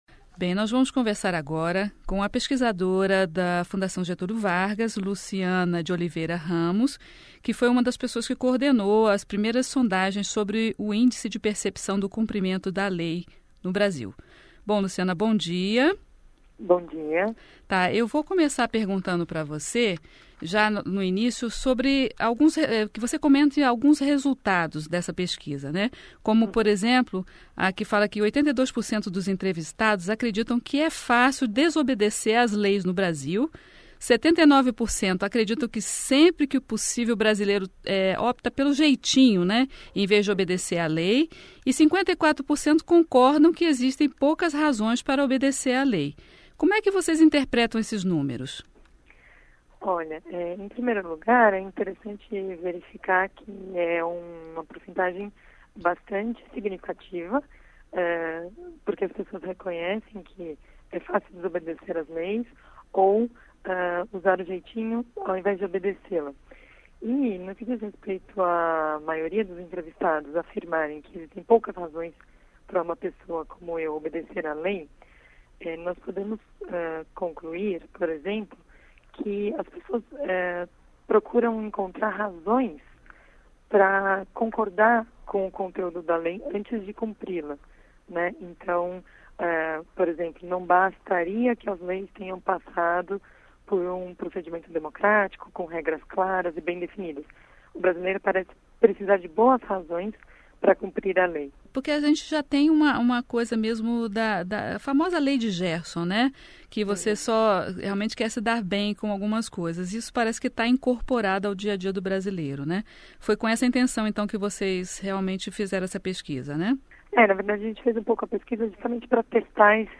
Entrevista: Índice de Percepção do Cumprimento de Leis no Brasil